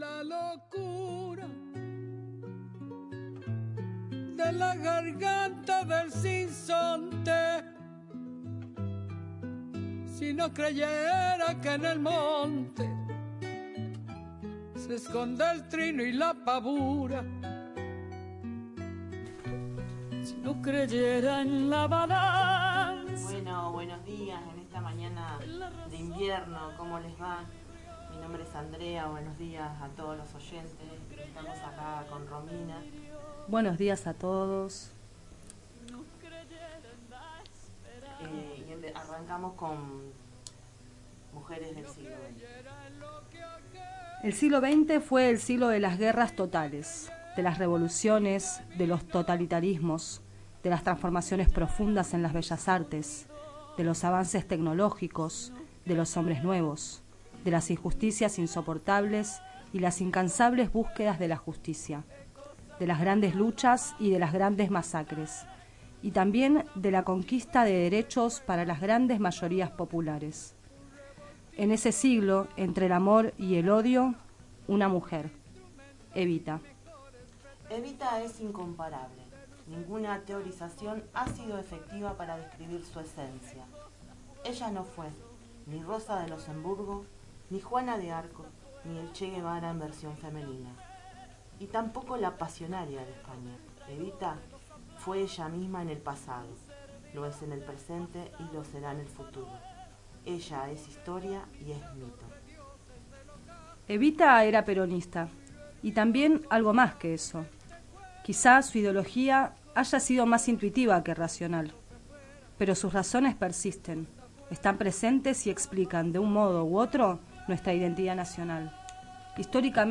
Un micro radial de la biblio-móvil comunitaria del Consejo de las Mujeres Tornquist.